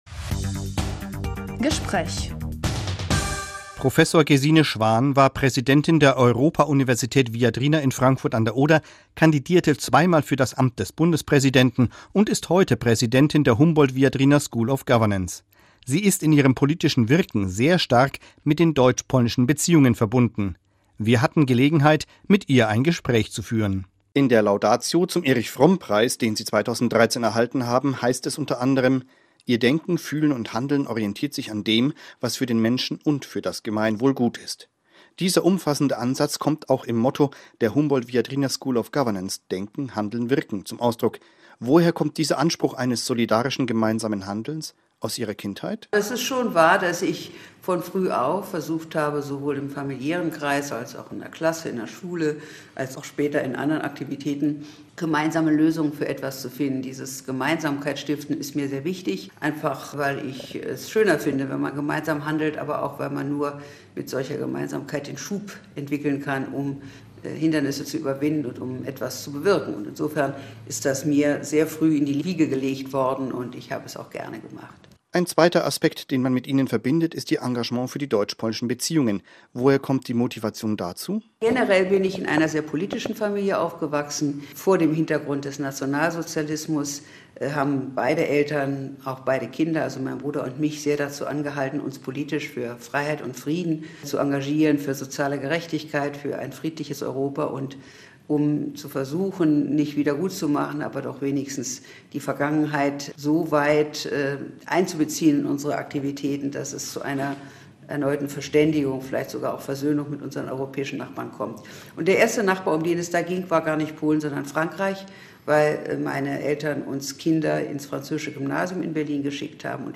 00:00 Gesine Schwan Interview 06:47 30 Jahre GFPS